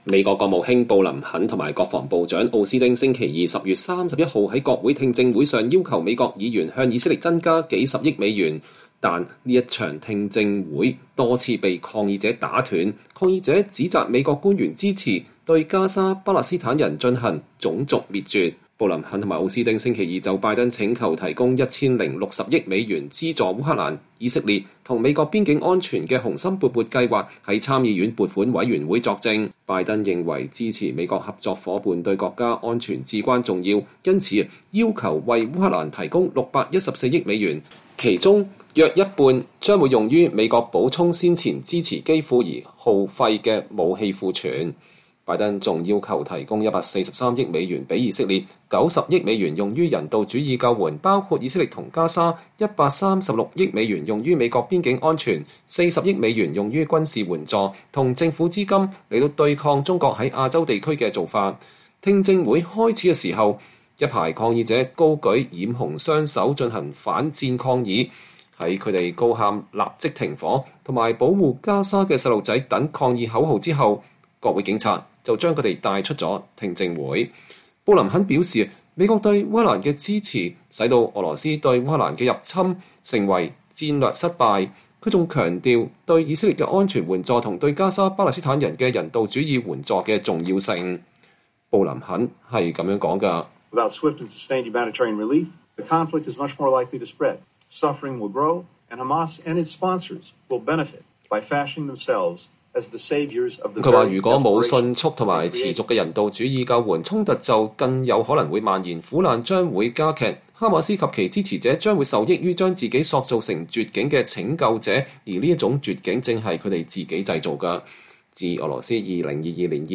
美國國務卿布林肯（Antony Blinken）和國防部長奧斯汀（Lloyd Austin）週二（10月31日）在國會聽證會上要求美國議員向以色列增加數十億美元，但這場聽證會多次被抗議者打斷，抗議者指責美國官員支持對加沙巴勒斯坦人進行“種族滅絕”。